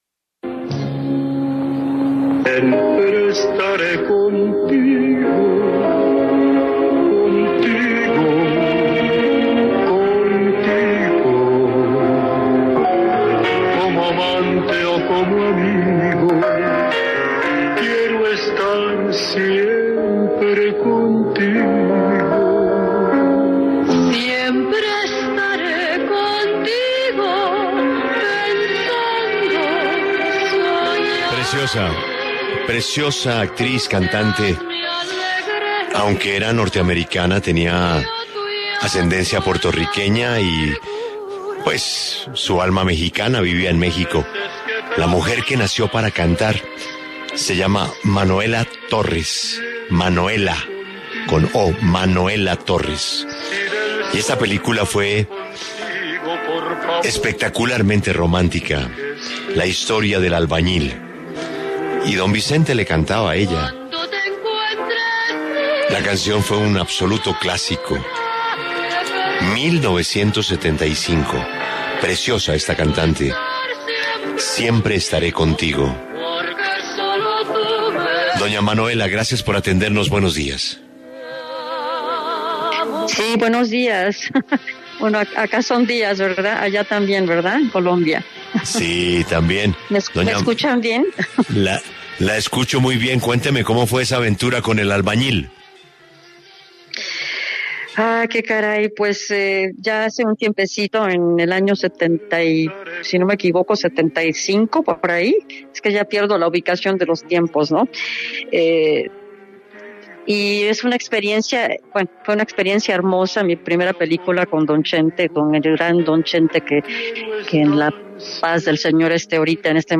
En el encabezado escuche la entrevista completa con Manoella Torres, cantante que trabajó con Vicente Fernández en la película “El Albañil”